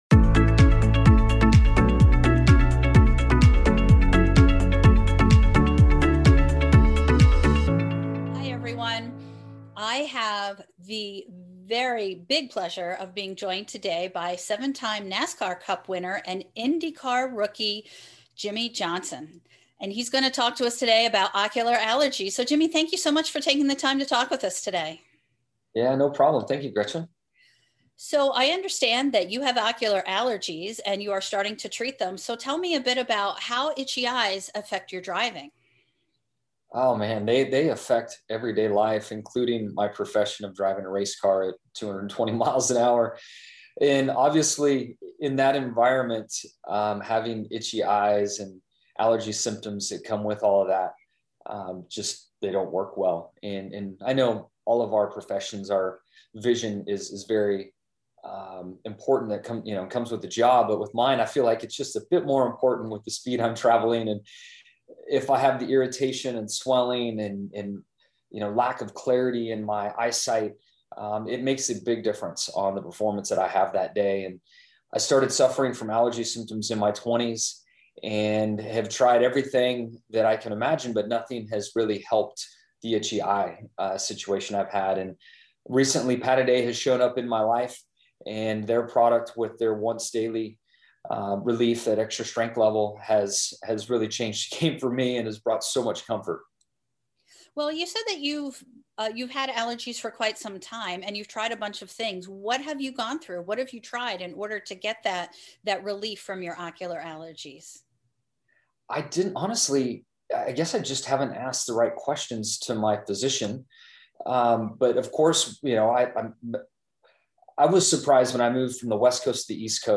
NASCAR winner Jimmie Johnson talks about how allergies affect his driving